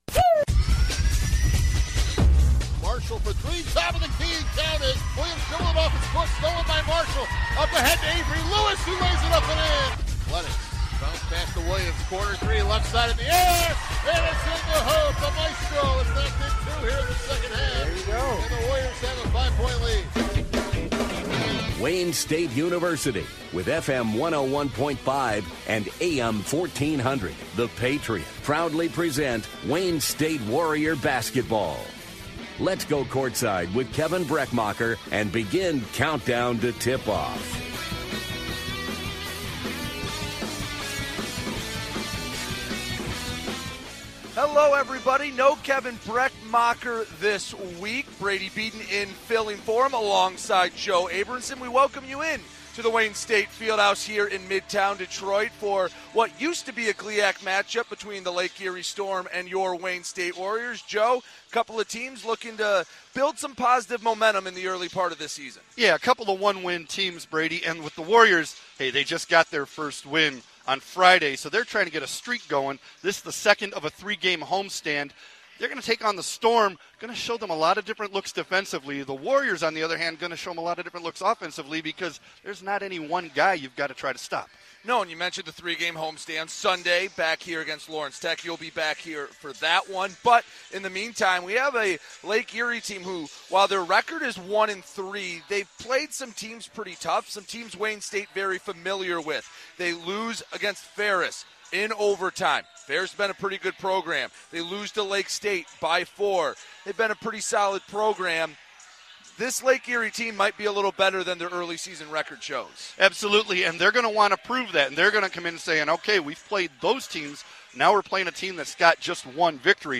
WDTK Broadcast of Men's Basketball vs. Lake Erie - Nov. 21, 2023